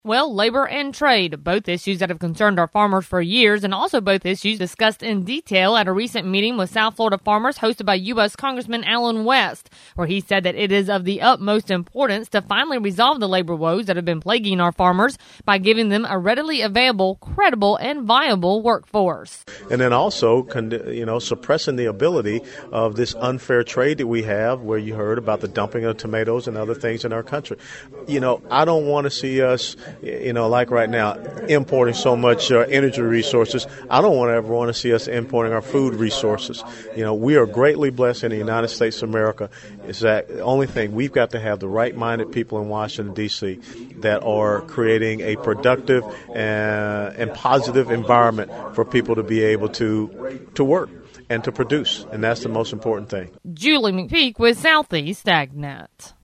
Trade is one of the top issues that has concerned our farmers for years and US Congressman Allen West addressed it recently at meeting with South Florida farmers.